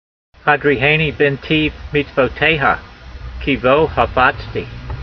Sound (Psalm 119:35) Transliteration: ha dreehay nee bee n teev meetsv o tey ha , kee- vo ha fats tee Vocabulary Guide: Lead me in the path of your commandment s: For I delight therein . Translation: Lead me in the path of your commandments: For I delight therein.